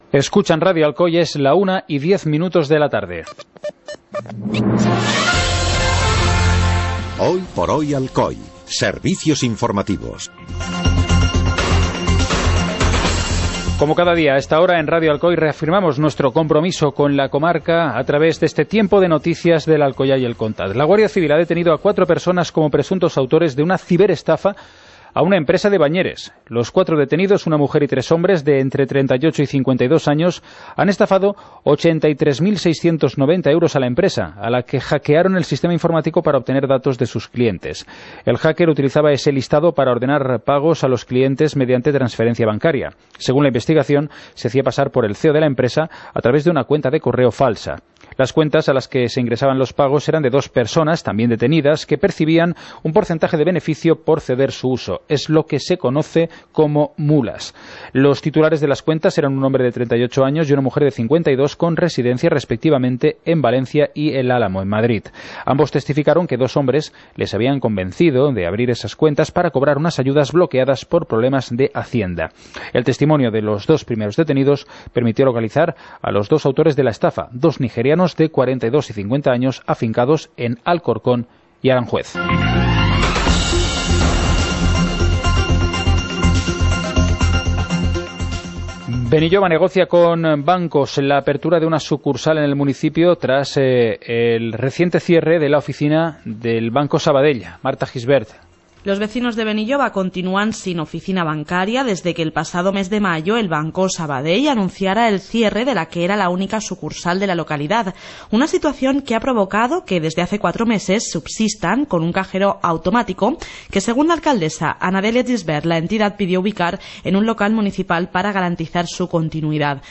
Informativo comarcal - martes, 10 de octubre de 2017